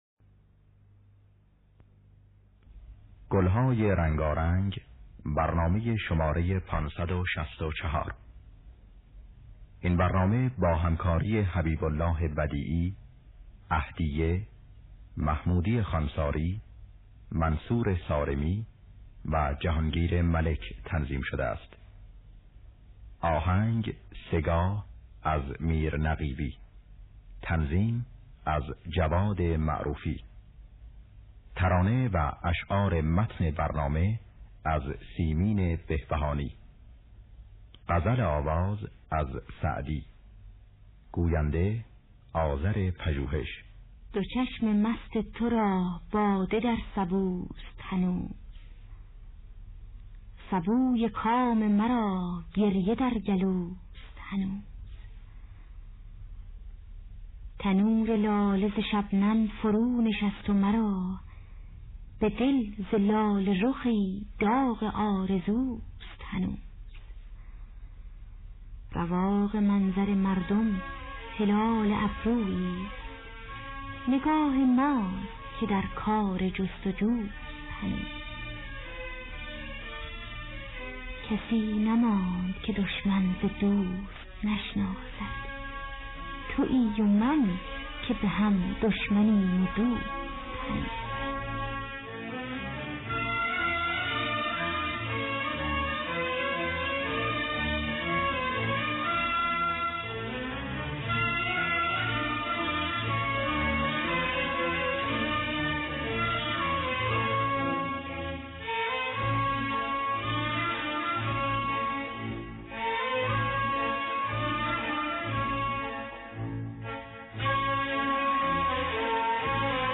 دانلود گلهای رنگارنگ ۵۶۴ با صدای محمودی خوانساری، عهدیه در دستگاه سه‌گاه.